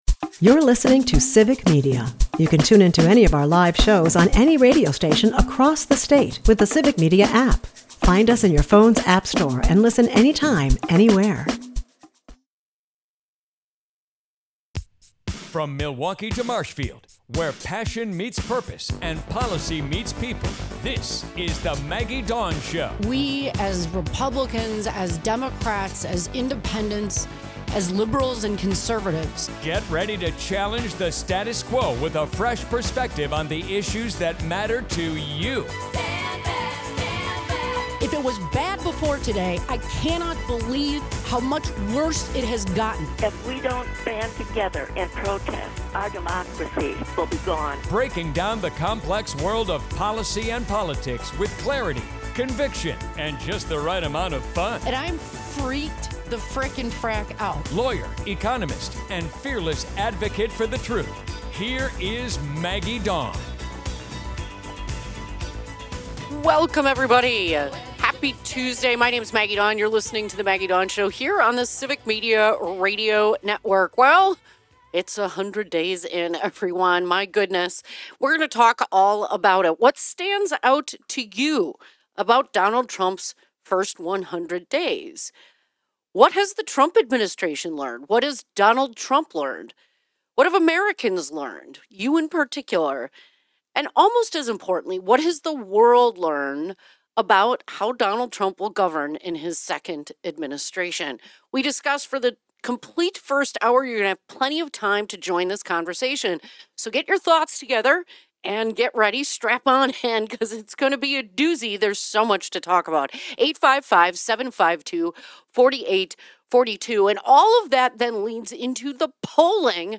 She also questions Trump’s governance style, equating it to a temper tantrum. Plus, listeners weigh in with failing grades for this current administration.